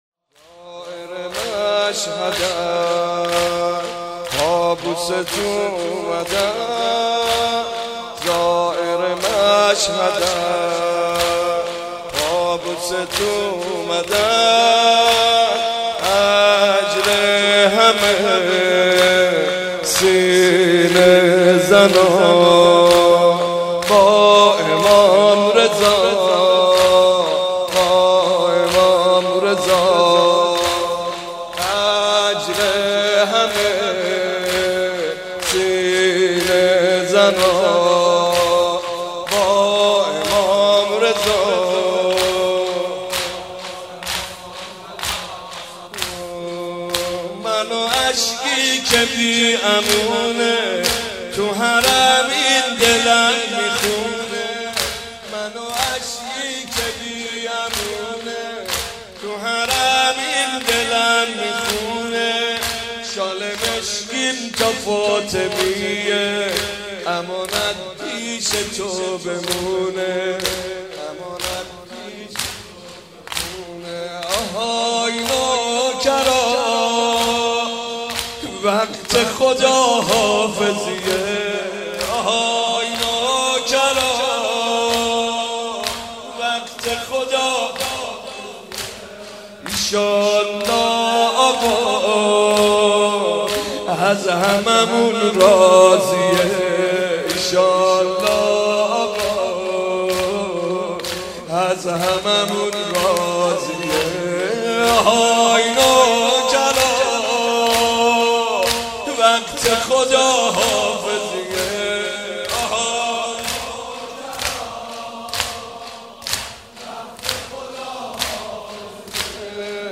گلچین بهترین مداحی حاج محمد رضا طاهری